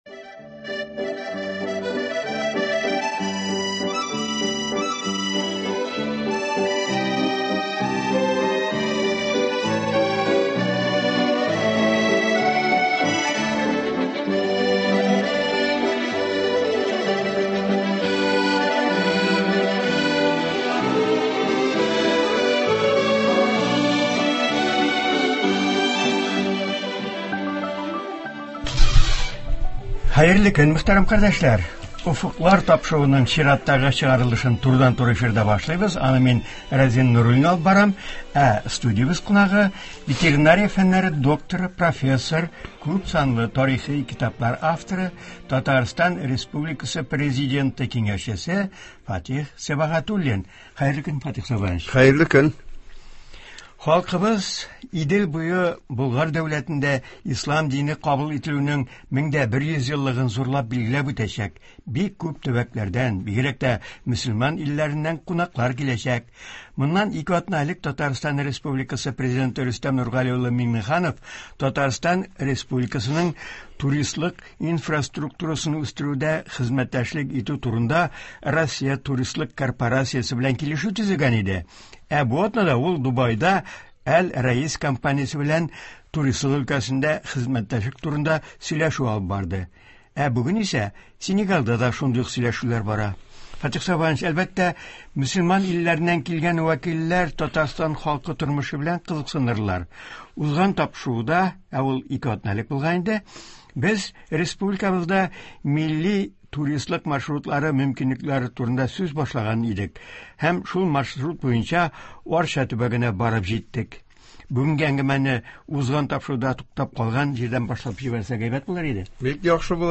Ветеринария фәннәре докторы, профессор, Татарстан Президенты киңәшчесе Фатих Сибагатуллин узган тапшыруда республикабызда булдырылачак туристлык маршрутлары турында сөйли башлаган иде. Чираттагы тапшыруда турыдан-туры эфирда шушы темага әңгәмә дәвам итә.